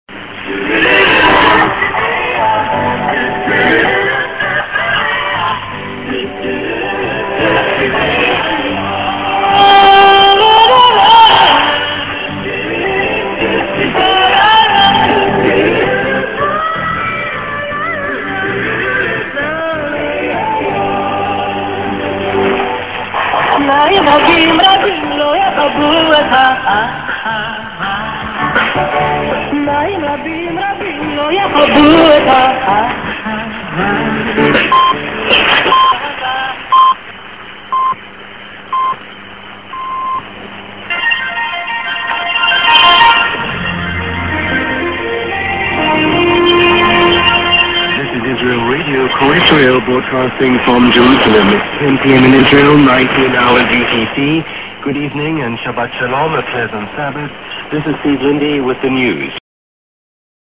Mid. music->40"TS->ID(man)